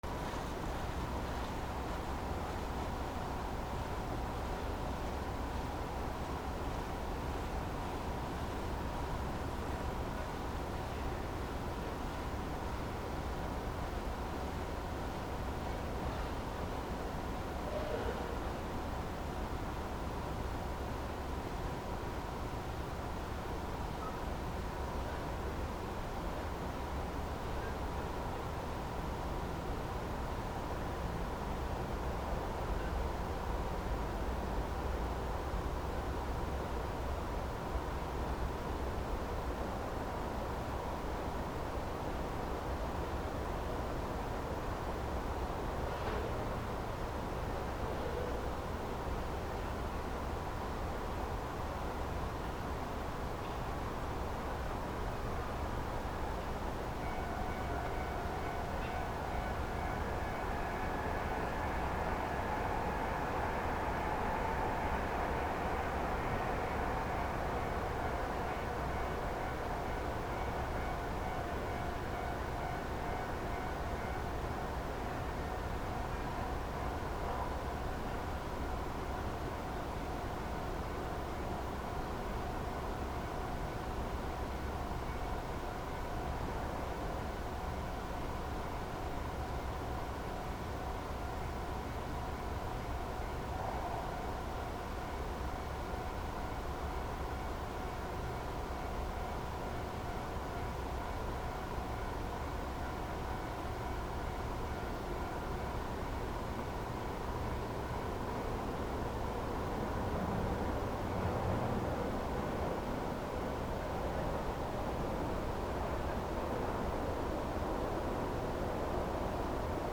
住宅街道路 環境音静かめ 自販機あり
/ C｜環境音(人工) / C-10 ｜環境音(道路)